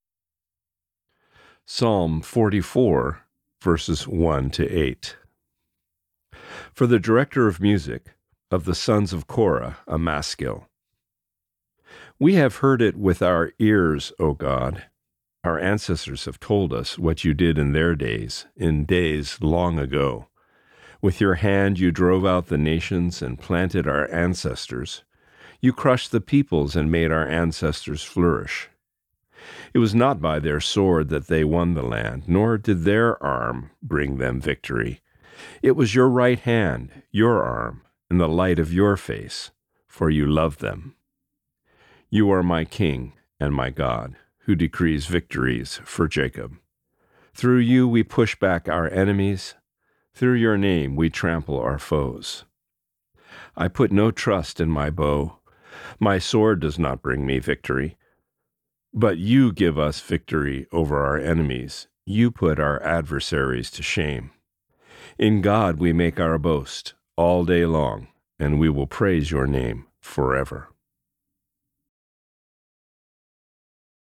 Reading: Psalm 44:1-8